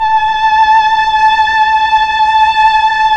Index of /90_sSampleCDs/Keyboards of The 60's and 70's - CD1/STR_Melo.Violins/STR_Tron Violins
STR_TrnVlnA_5.wav